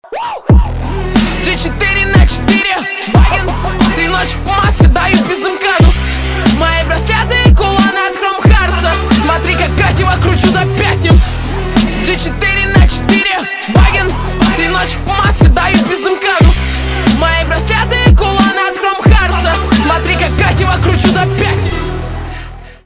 русский рэп
басы , качающие